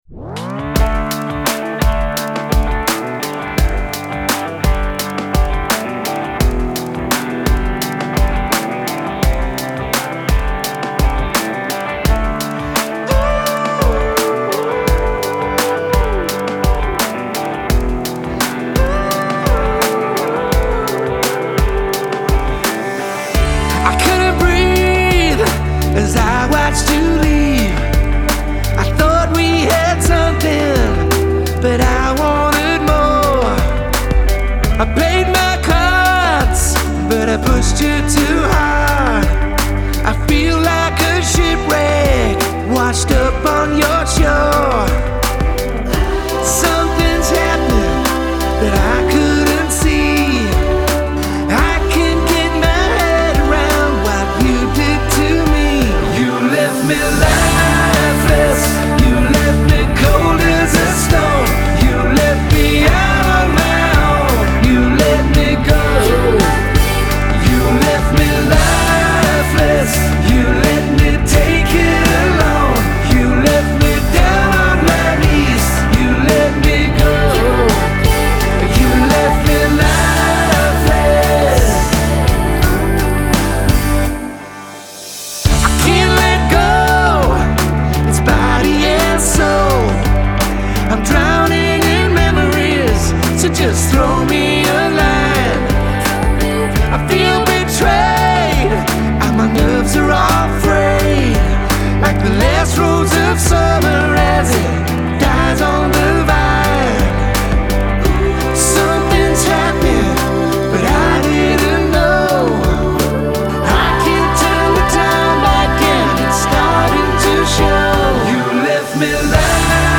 Genre : Rock, Pop